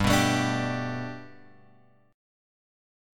Gadd9 chord {3 2 x 2 3 3} chord